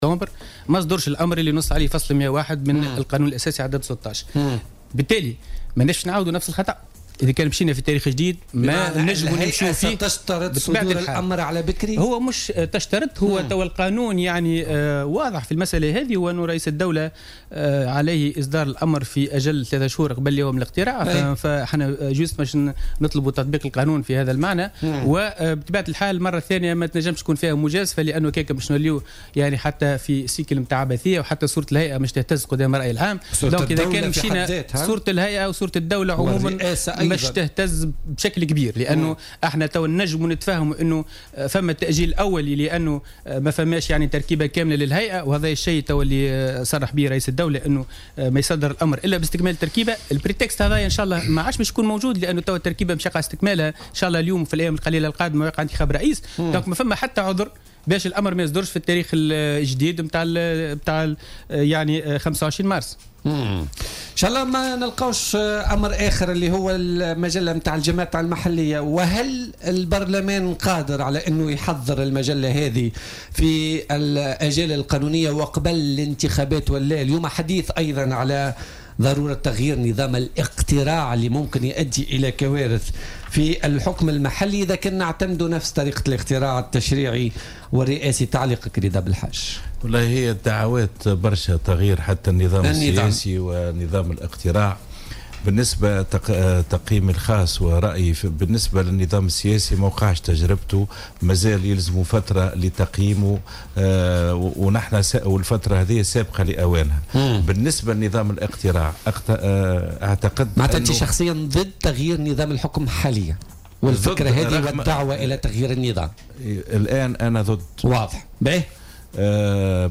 قال رئيس الهيئة التأسيسيّة لحركة تونس أولا رضا بلحاج ضيف بولتيكا الأربعاء إنه ليس مع تغيير نظام الحكم اليوم لكنه يساند تغيير نظام الإقتراع.